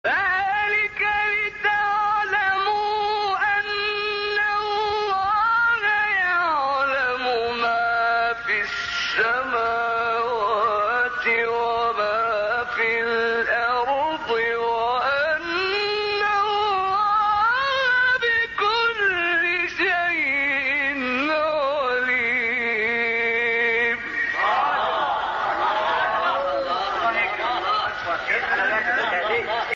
شبکه اجتماعی: مقاطع صوتی از تلاوت قاریان برجسته مصری ارائه می‌شود.